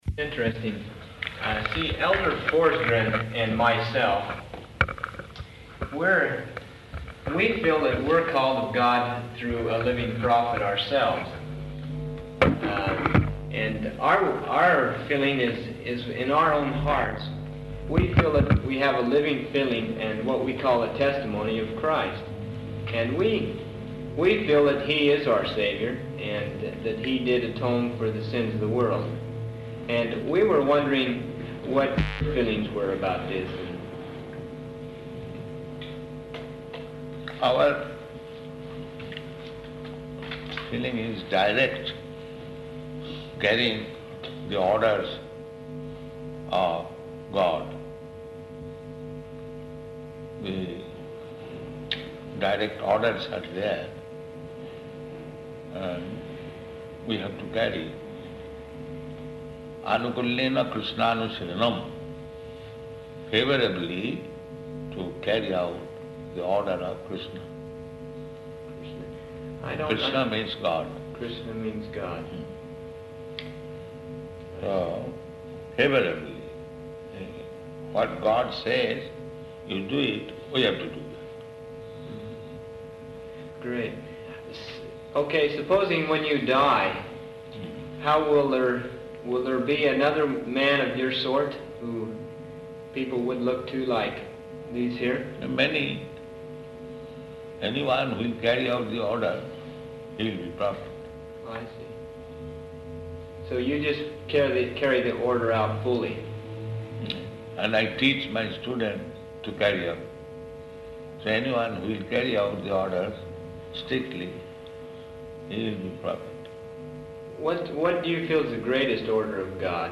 Room Conversation with Mormons